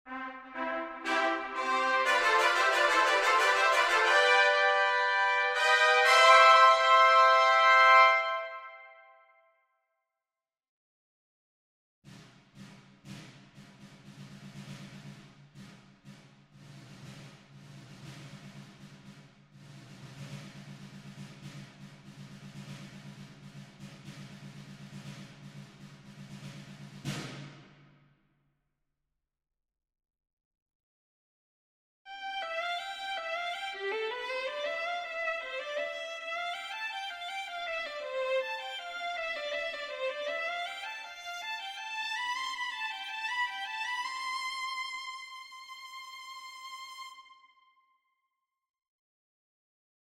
05-Sonidos-intrumentos-individuales.mp3